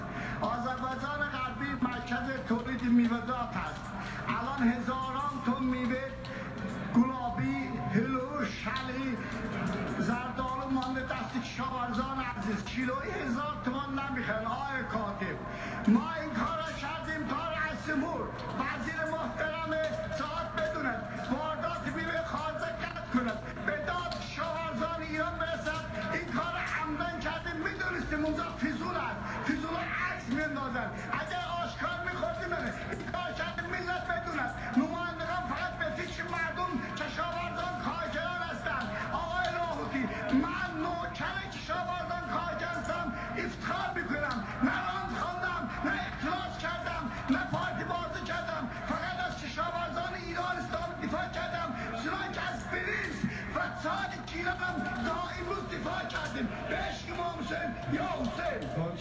از قصد اینطوری با لحجه غلیظ و در سطح سواد یه بیسواد روستایی صحبت میکنه چون میدونه براش رای میاره